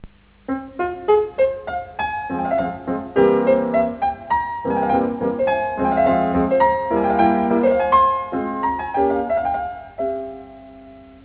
中期のソナタではほとんど全ての主題は上昇するデザインを持っている。